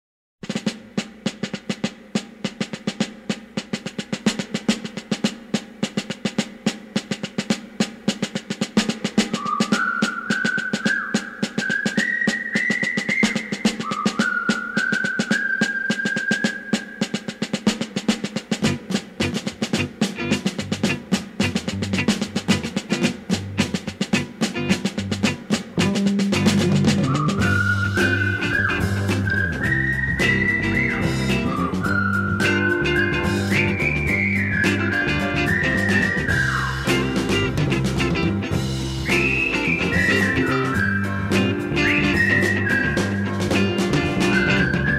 and a jazzy, whistling march for the film's titular heroes.